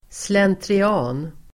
Ladda ner uttalet
Uttal: [slentri'a:n]